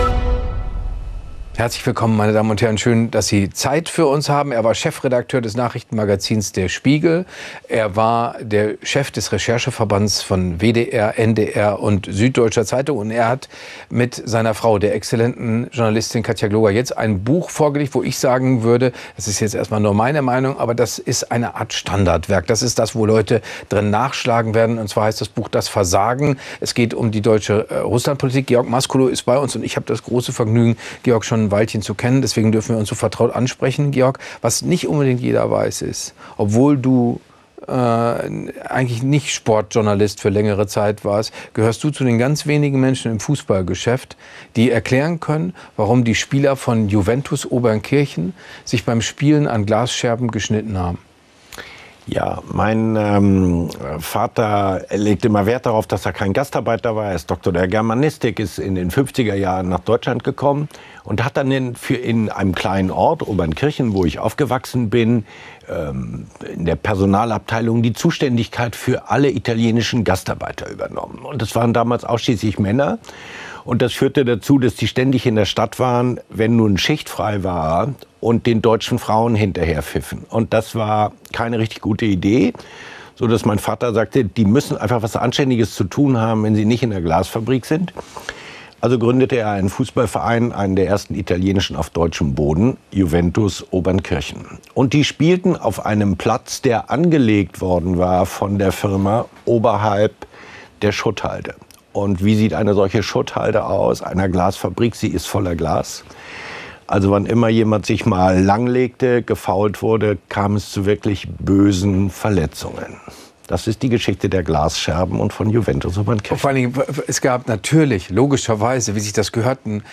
Jörg Thadeusz spricht mit dem Investigativ-Journalisten Georg Mascolo über den Abend des Mauerfalls sowie über tiefgreifende Fehler der deutschen Russlandpolitik und deren historische Konsequenzen.